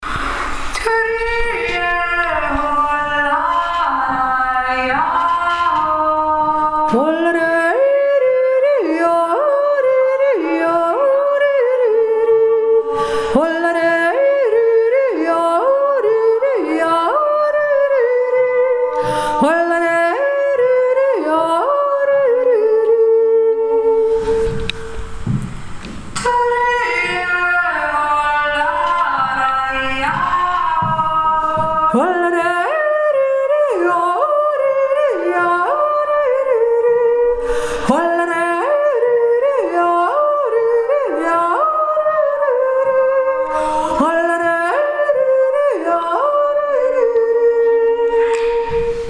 Schuldirndl - Jodler
2. Stimme